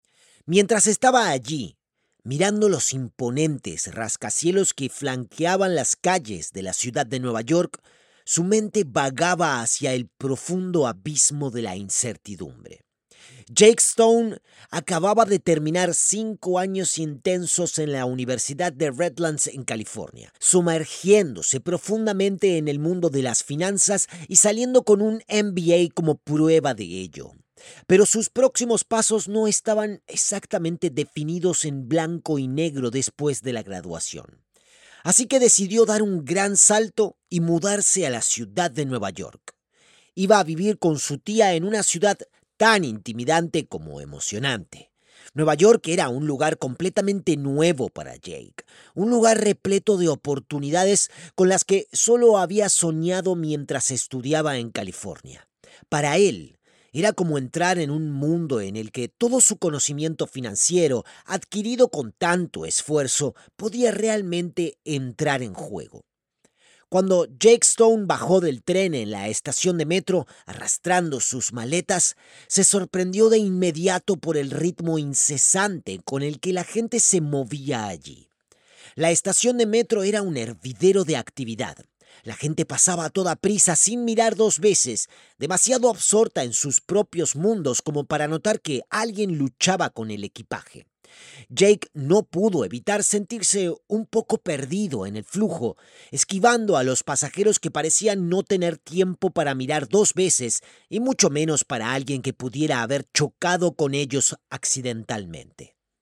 Espagnol (argentin)
Démo commerciale